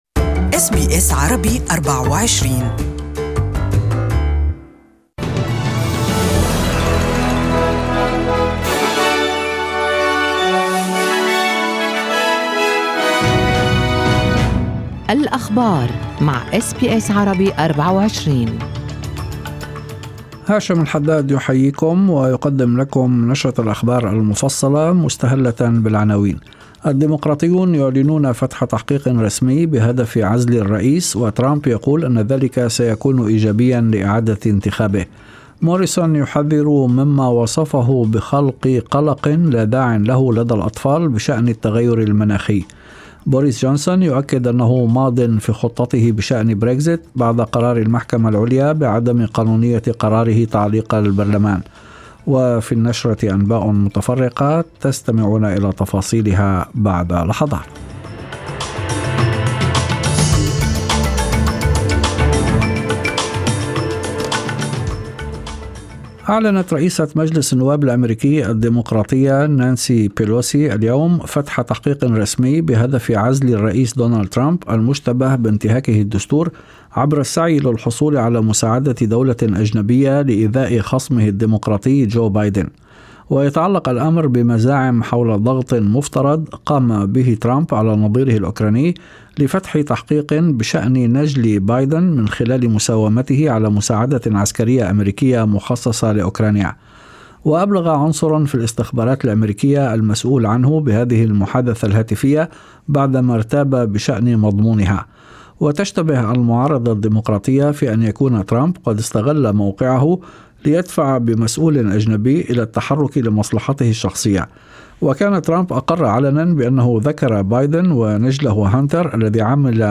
Evening News: Western Australia government closes on assisted dying bill